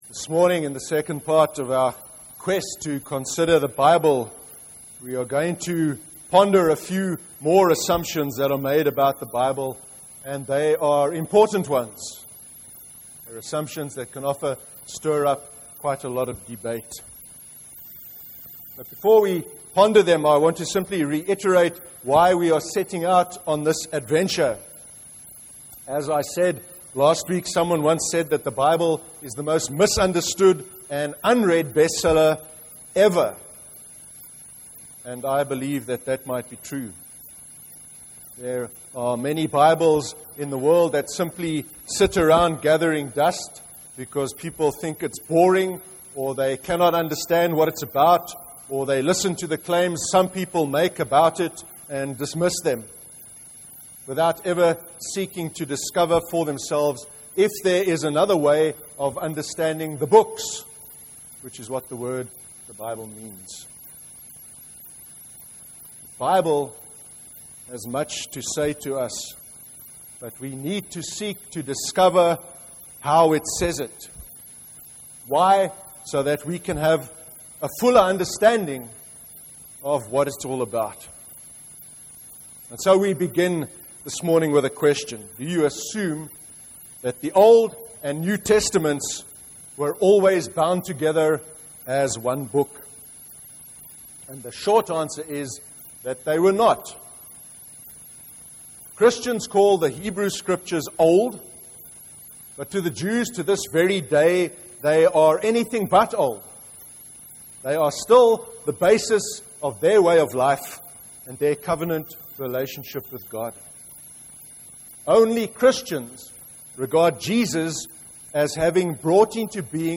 25/01/2015 sermon: The Bible part 2 – NEWHAVEN CHURCH
25/01/2015 sermon: The Bible part 2